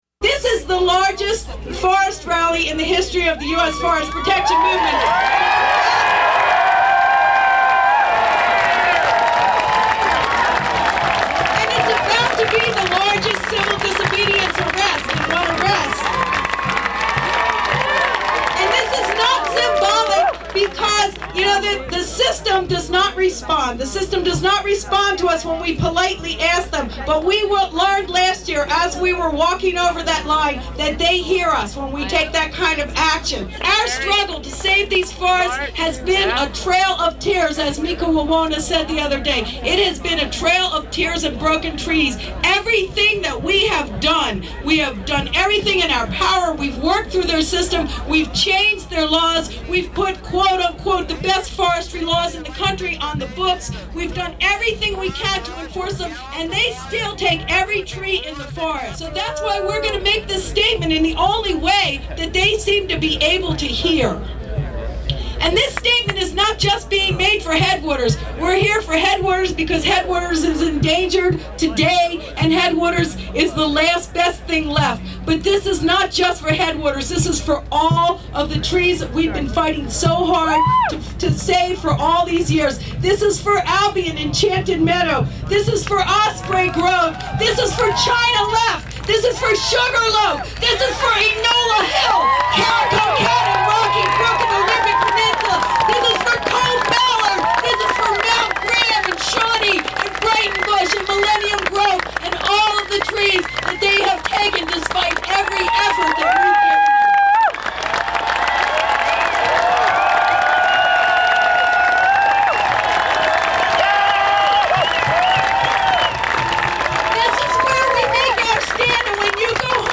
• Headwaters Forest Rally, 9/15/96 -
HeadwatersRally9_15_96.mp3